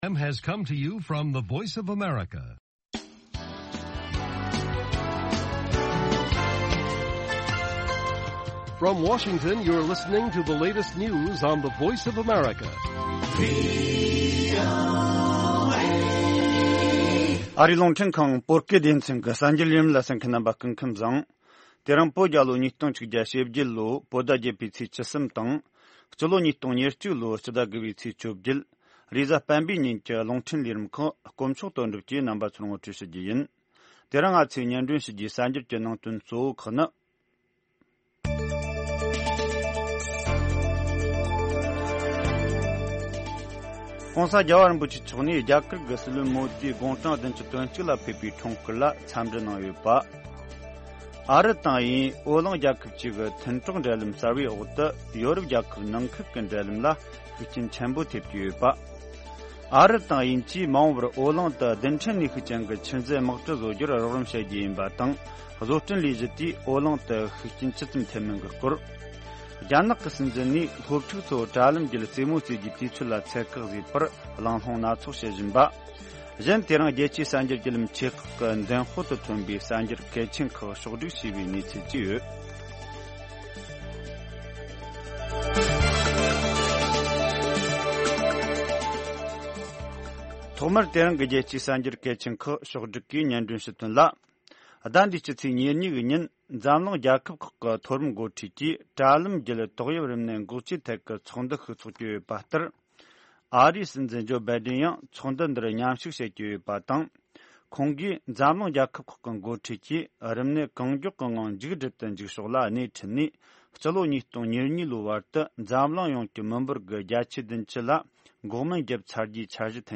དགོང་དྲོའི་རླུང་འཕྲིན།